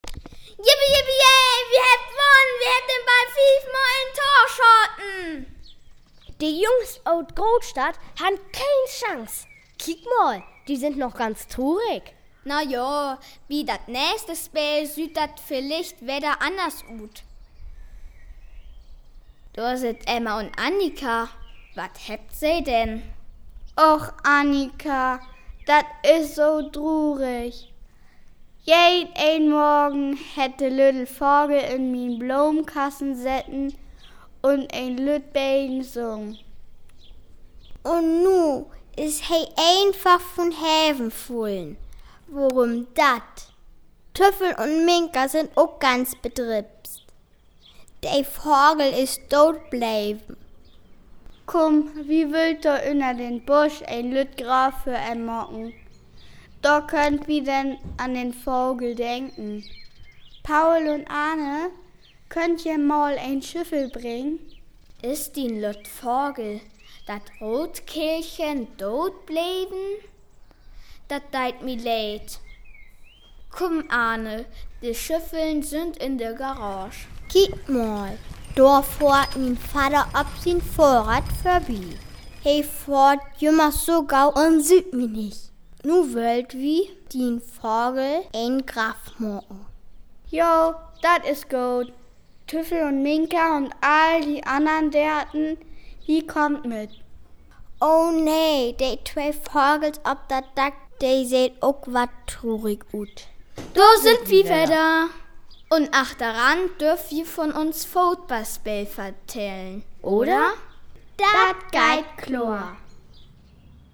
Dialog Paul un Emma - Lex 15 (MP3) (2,6 MiB)